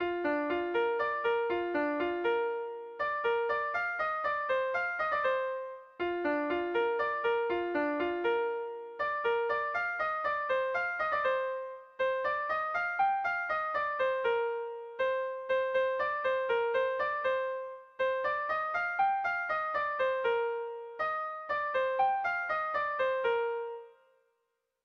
Erlijiozkoa
AAB1B2